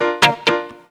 PIANO+GTR1-L.wav